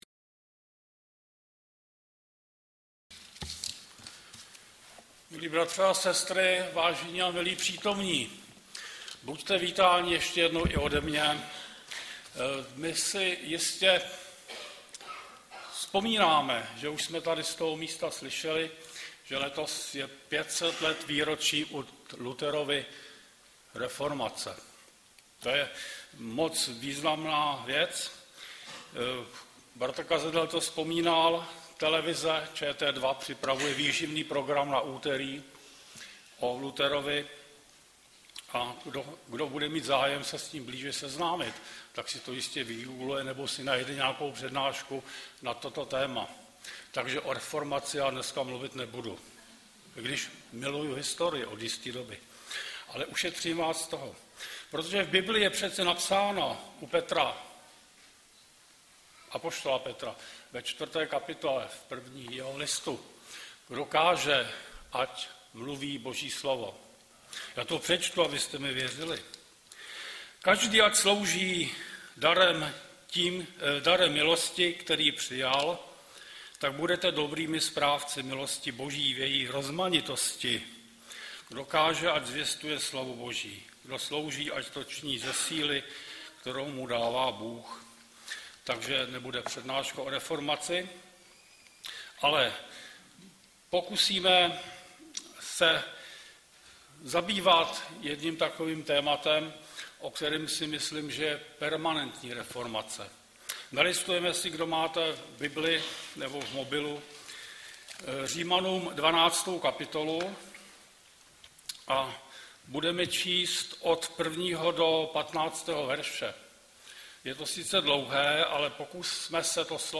Webové stránky Sboru Bratrské jednoty v Litoměřicích.
Kázání